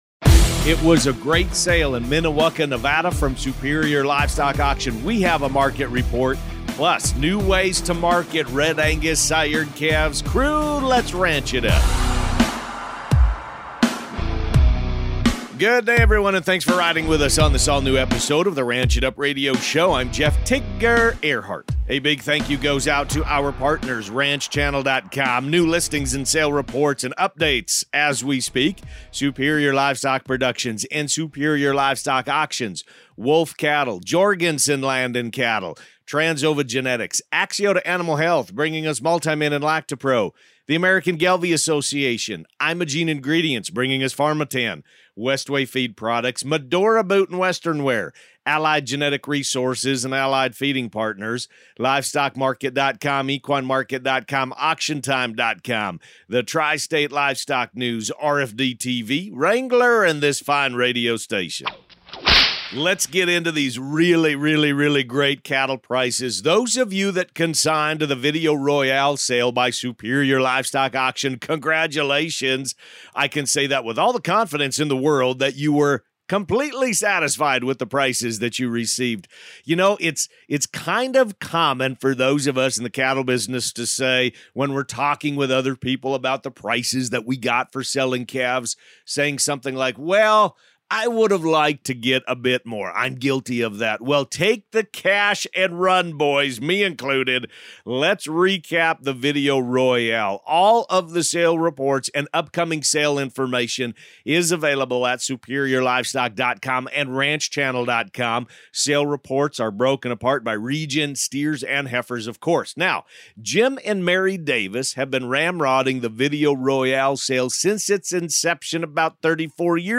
market report